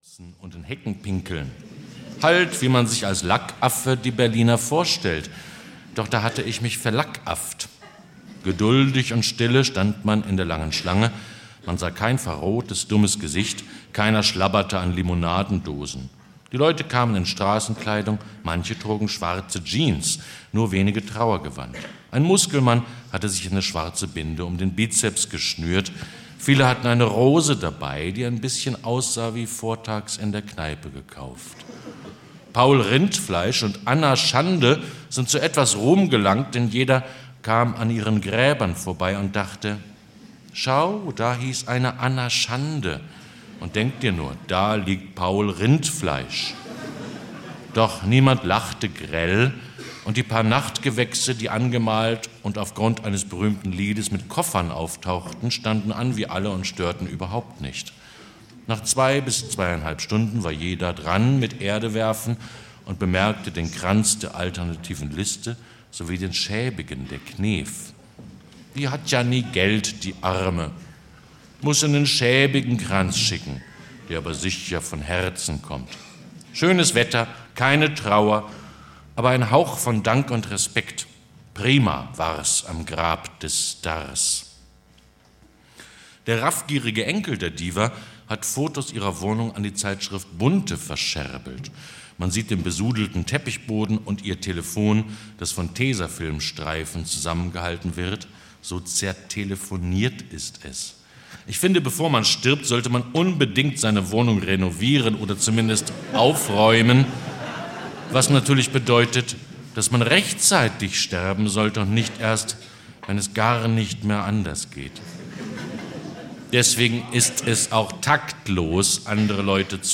Für Nächte am offenen Fenster - Max Goldt - Hörbuch